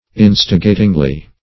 instigatingly - definition of instigatingly - synonyms, pronunciation, spelling from Free Dictionary Search Result for " instigatingly" : The Collaborative International Dictionary of English v.0.48: Instigatingly \In"sti*ga`ting*ly\, adv.
instigatingly.mp3